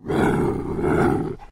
sounds / monsters / cat